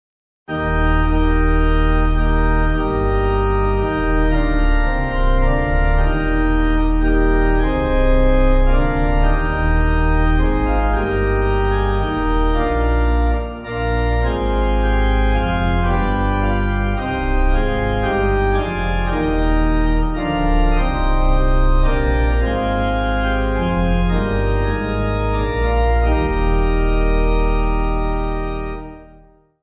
(CM)   5/Eb